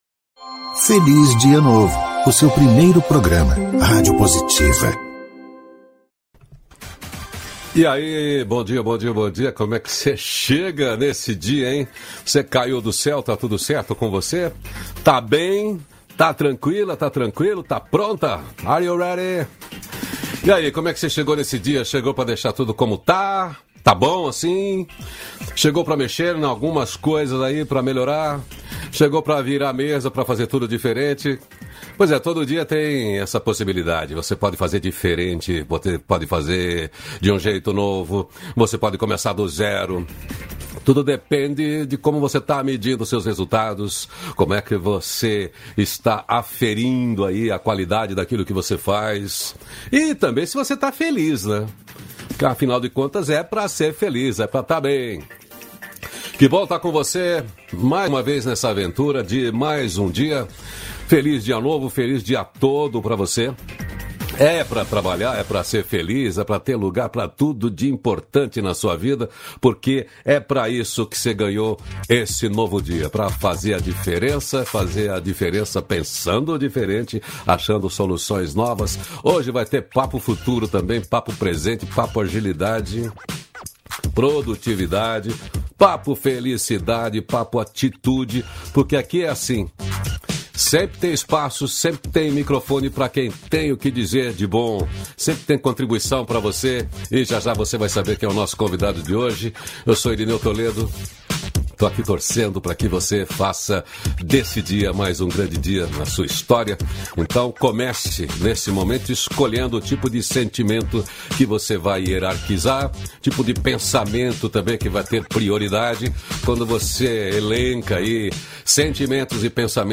328-feliz-dia-novo-entrevista.mp3